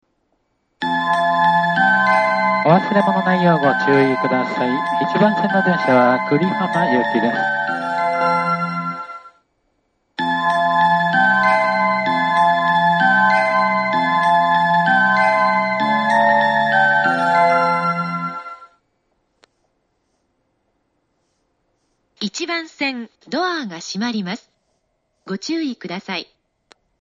１番線発車メロディー 曲は「あざみ野」です。